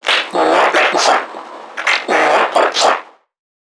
NPC_Creatures_Vocalisations_Infected [88].wav